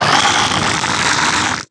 zling_bat1.wav